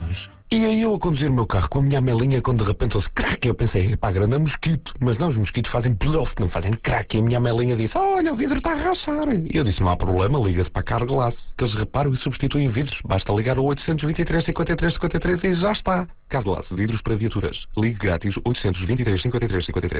Esta campanha passa na RFM e RC e tem 3 spots diferentes (